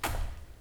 footsteps sounds
foot_step_2.wav